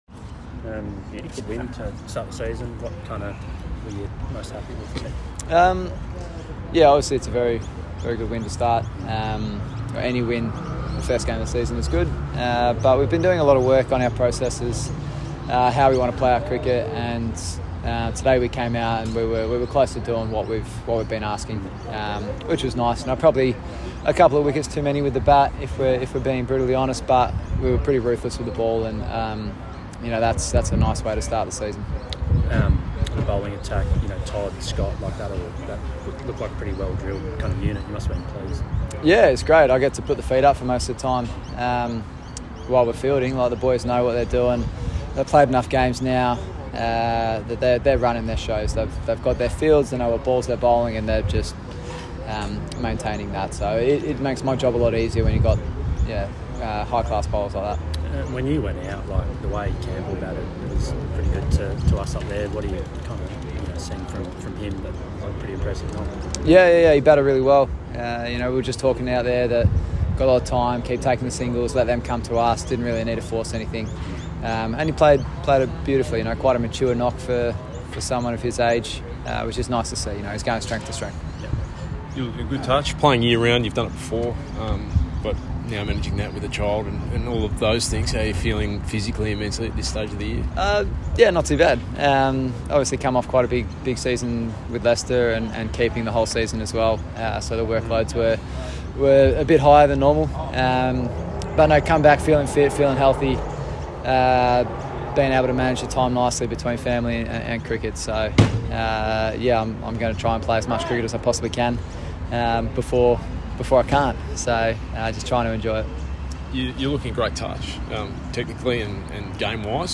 Victora's Peter Handscomb (51) spoke after Victoria won by 3 wickets.